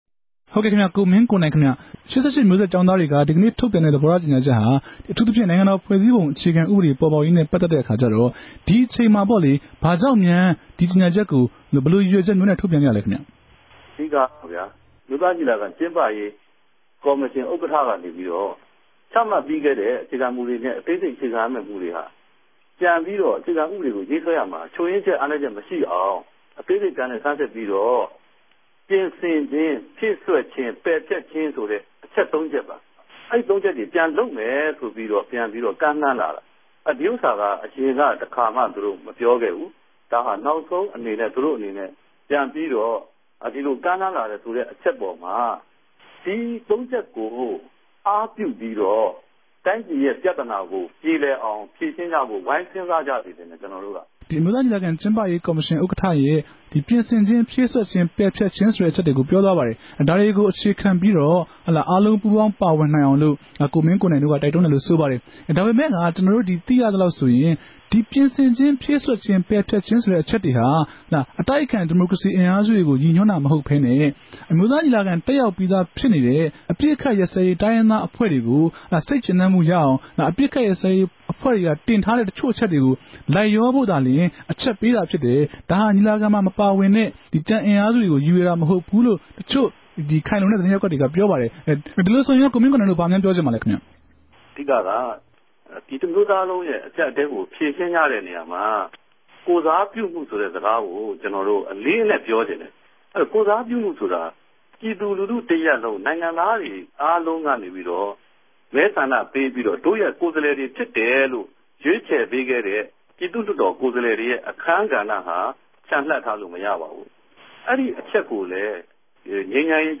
ဒီေုကညာခဵက်နဲႛ ပတ်သက်လိုႛ ၈၈ မဵြိးဆက် ကေဵာင်းသားခေၝင်းဆောင် ကိုမင်းကိိုံိုင်က ခုလို ေူပာပၝတယ်။